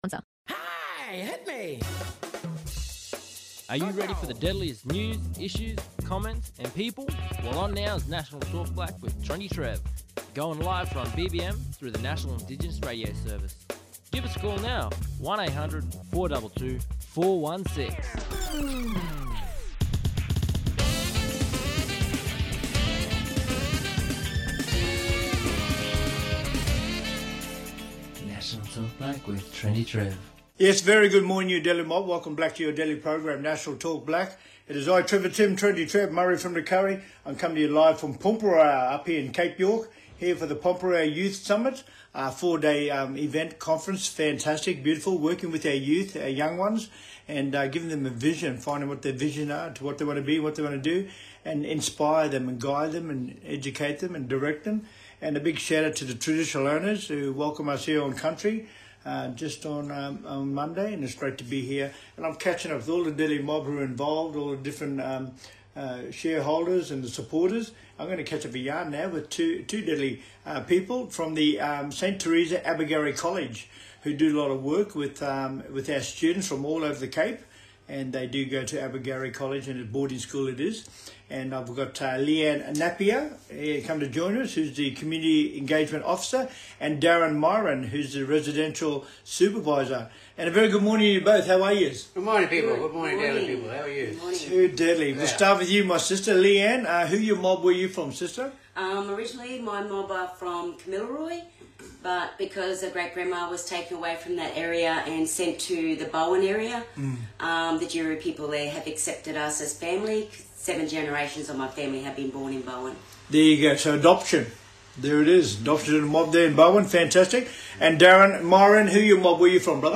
On todays National Talk Black via NIRS – National Indigenous Radio Service we have:
He yarns with guests about the work they do, their involvement with the summit and what they are expecting from their time there.